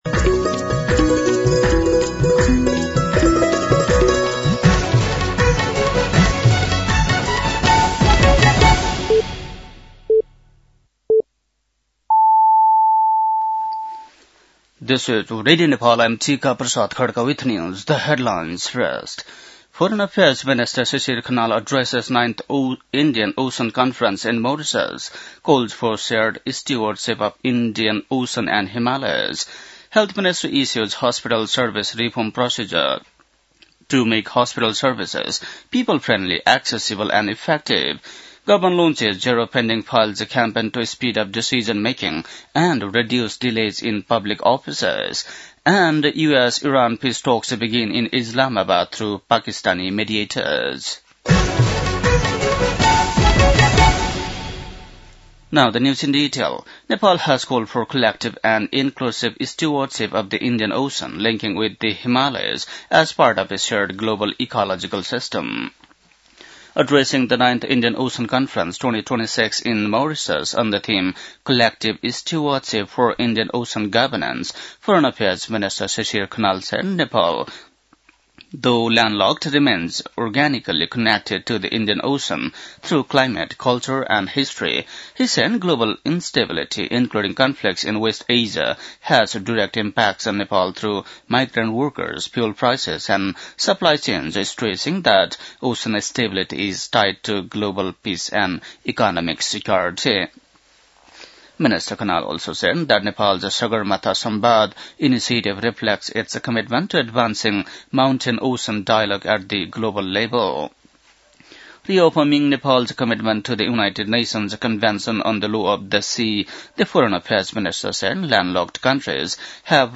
बेलुकी ८ बजेको अङ्ग्रेजी समाचार : २८ चैत , २०८२
8-pm-news-12-28.mp3